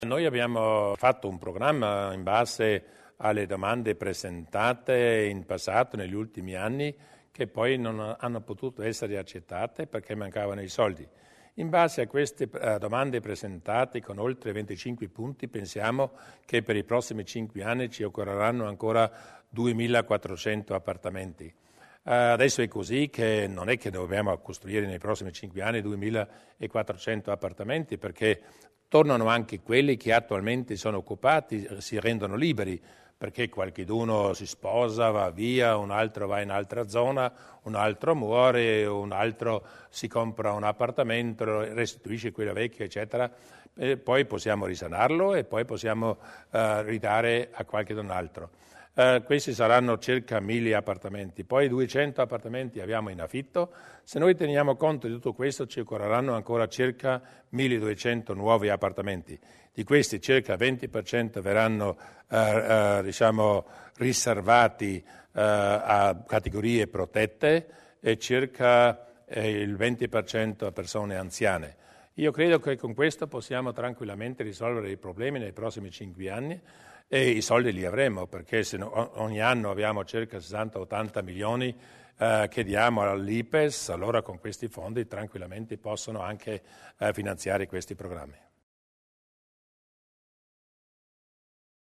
Il Presidente Durnwalder sul programma IPES